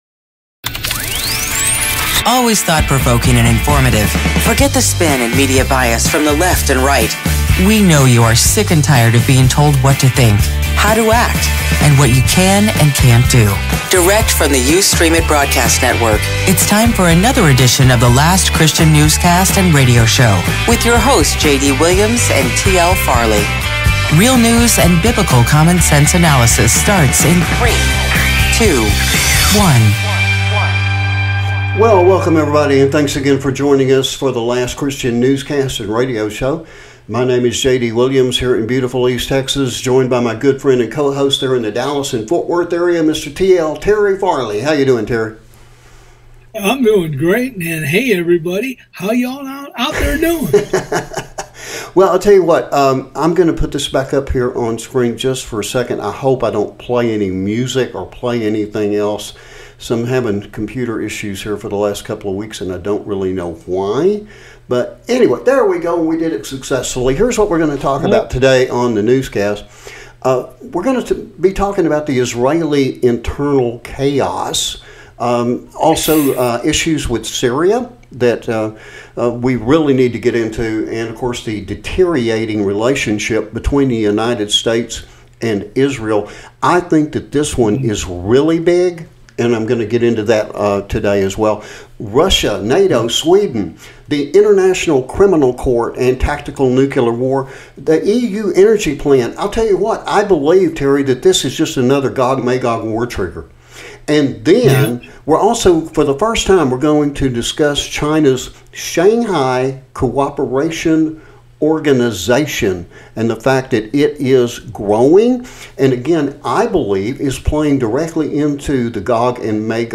Current Events and News from a Christian Perspective Today on the Newscast 1.